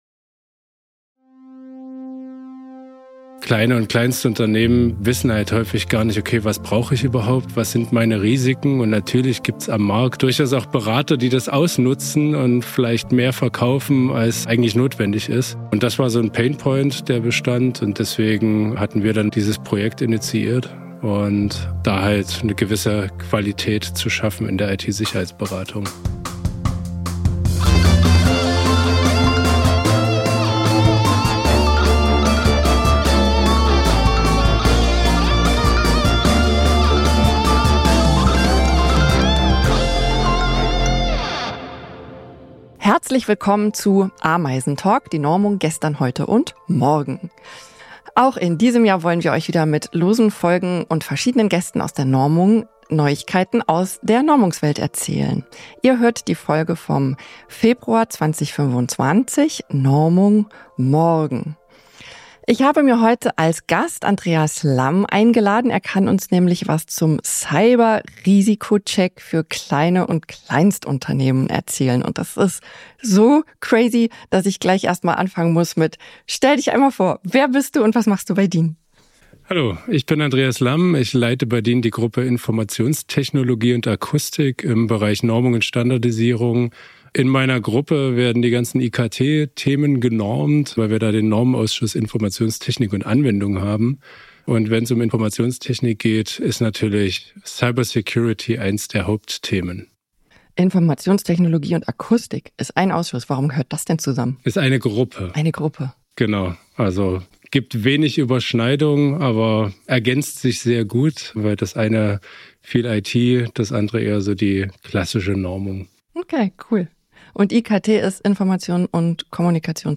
In dieser Interviewreihe stellen wir euch echte DINies vor - Mitarbeitende von DIN, die spannende Normungsgeschichten erzählen.